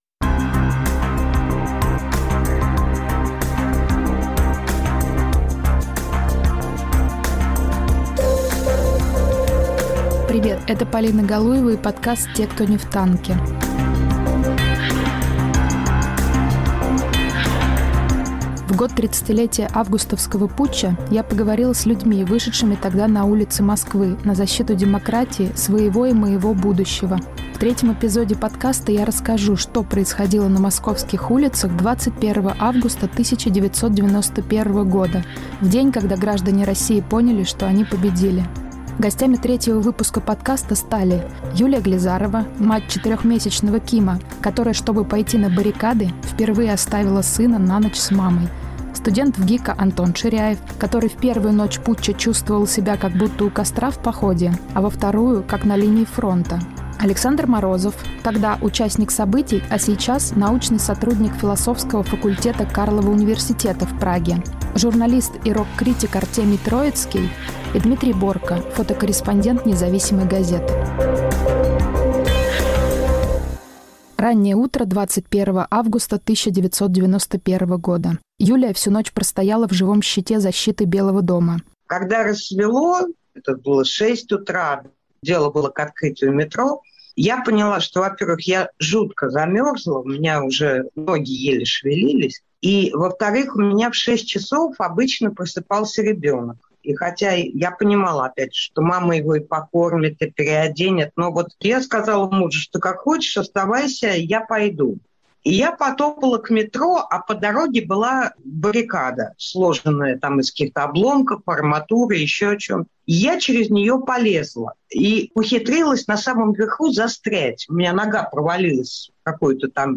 Защитники Белого дома рассказывают о том, что происходило в столице СССР в день победы. Повтор эфира от 21 августа 2021 года.